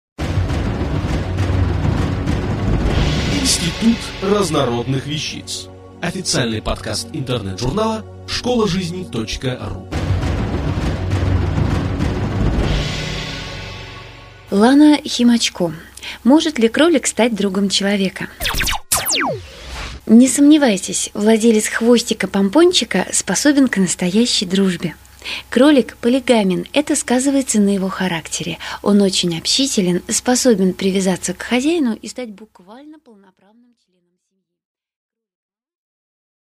Аудиокнига Может ли кролик стать другом человека? | Библиотека аудиокниг